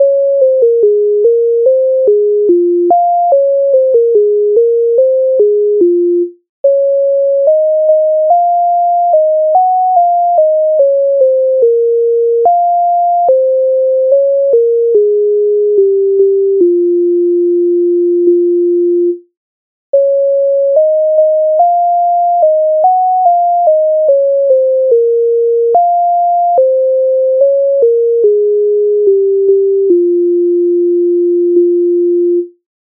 MIDI файл завантажено в тональності b-moll
Сивий голубочку Українська народна пісня з обробок Леонтовича с. 152